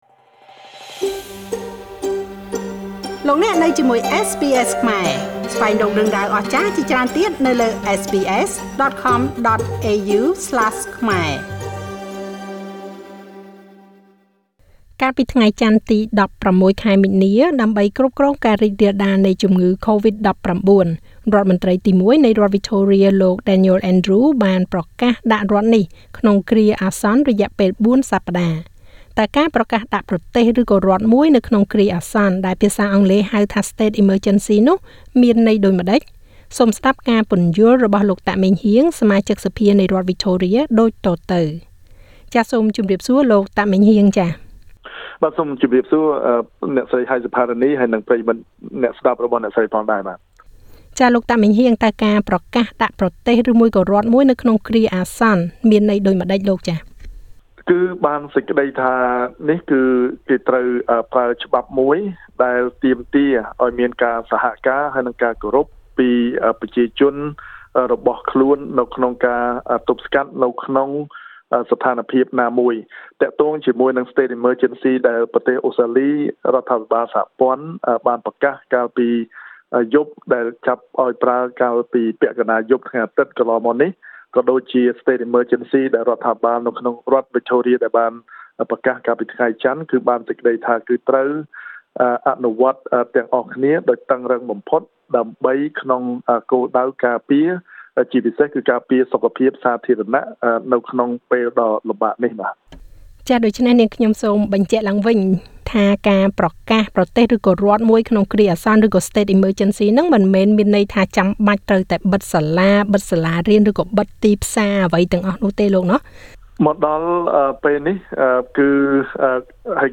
សូមស្តាប់ការពន្យល់របស់លោក តាកម៉េងហ៊ាង សមាជិកសភានៃរដ្ឋវិចថូរៀដូចតទៅ។
Victorian MP Meng Heang Tak at SBS studio in Melbourne Source: SBS Khmer